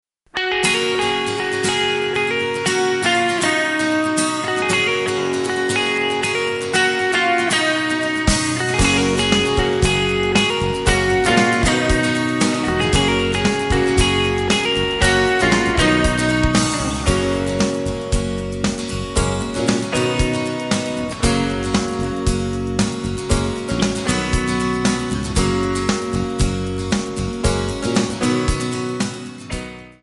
Backing tracks